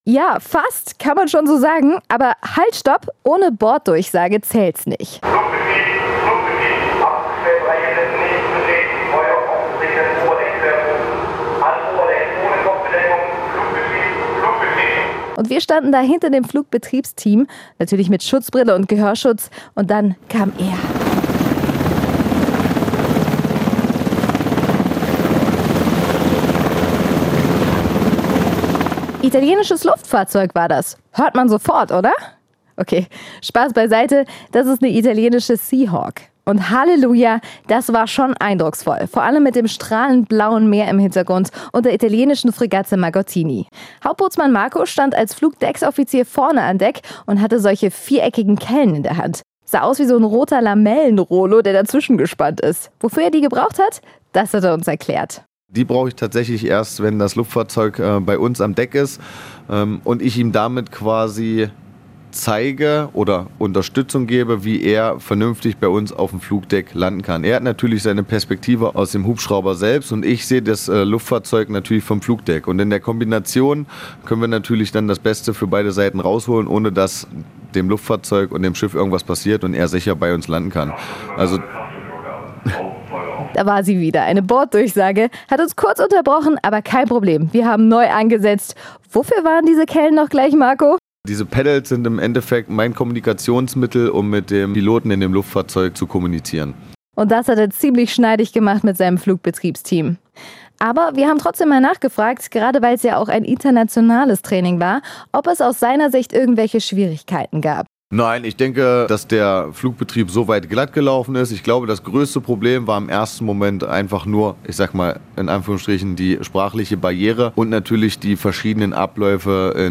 Mit Schutzbrille und Gehörschutz konnte unser Korrespondenten-Team auf dem EGVEinsatzgruppenversorger Bonn beobachten, wie ein italienischer Sea Hawk vor strahlend blauen Himmel von der Fregatte Margottini aus gestartet ist und auf dem Flugdeck der Bonn landete. Unser Korrespondenten-Team hat das Gesehene in Ton eingefangen.